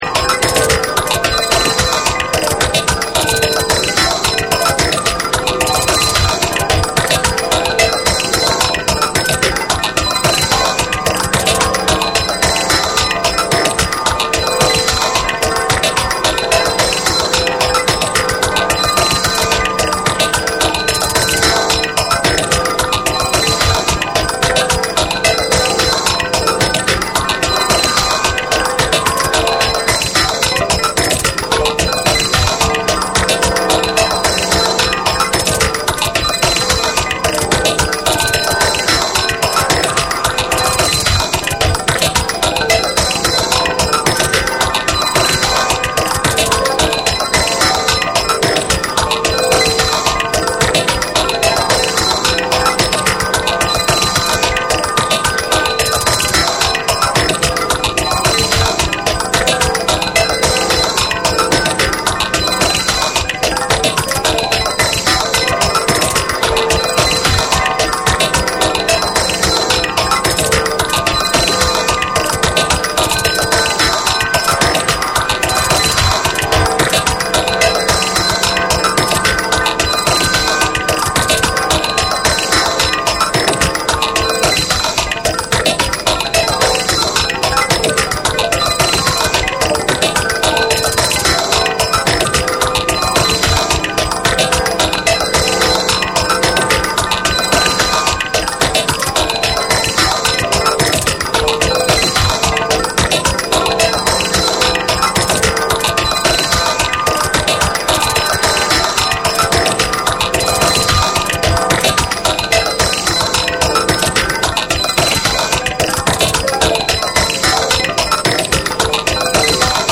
JAPANESE / BREAKBEATS